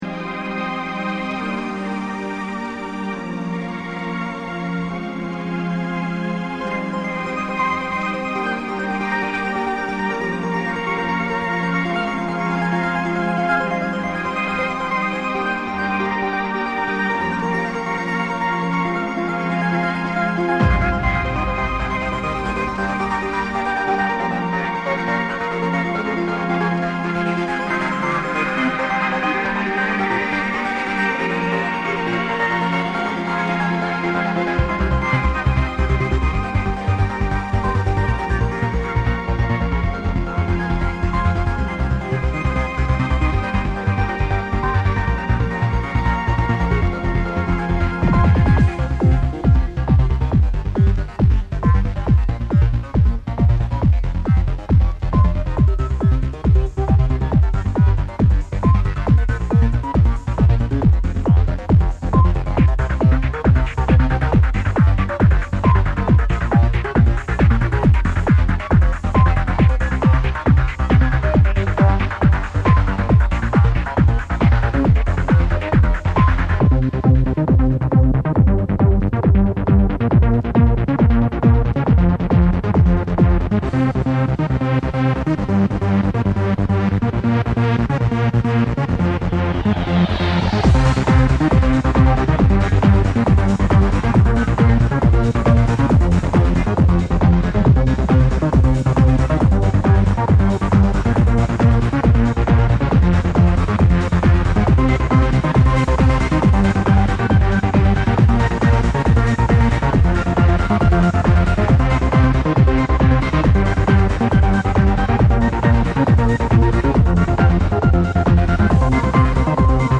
just awful quality